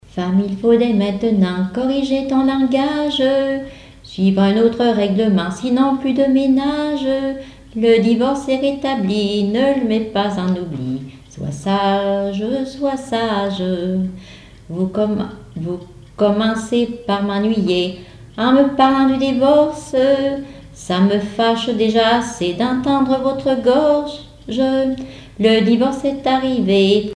Genre strophique
chansons et témoignages parlés
Pièce musicale inédite